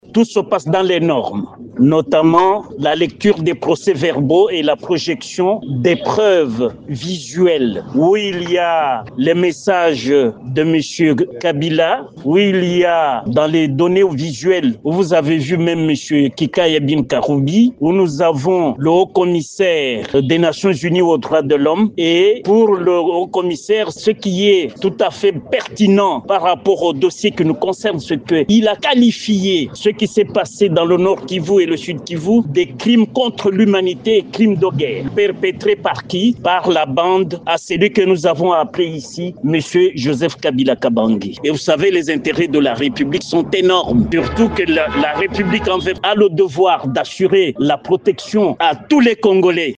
Lors de l’audience publique du jeudi 31 juillet du procès Joseph Kabila, la Haute Cour militaire a projeté plusieurs vidéos à charge de l’ancien Chef de l’État congolais, poursuivi pour de multiples infractions.